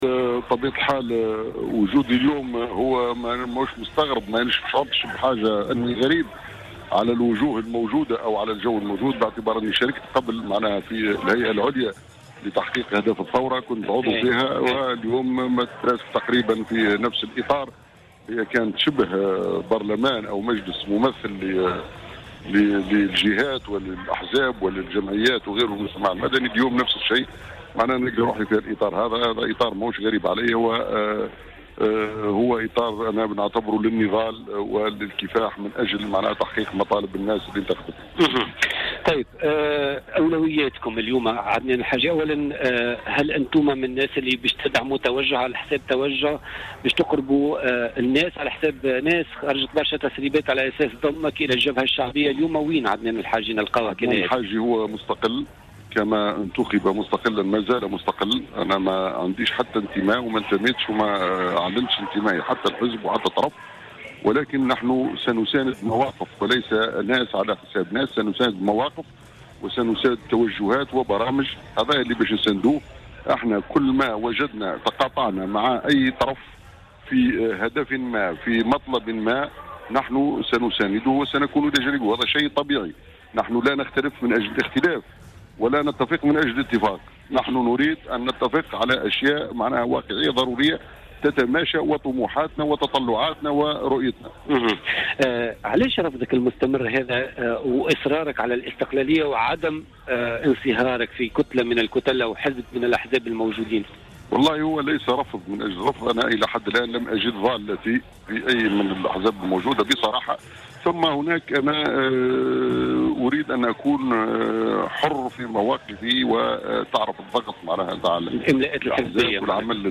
أكد النائب المستقل عن قائمة "رد الاعتبار" عدنان الحاجي في مداخلة له في برنامج "بوليتيكا" أنه لم يعلن انتمائه لأي حزب سياسي وأنه سيبقى نائبا مستقلا.